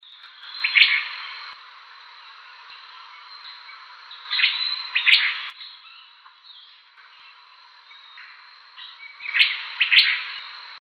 Fiofío Oliváceo (Elaenia mesoleuca)
Nombre en inglés: Olivaceous Elaenia
Fase de la vida: Adulto
Localidad o área protegida: Reserva Privada y Ecolodge Surucuá
Condición: Silvestre
Certeza: Vocalización Grabada
FIO-FIO-OLIVACEO-2.MP3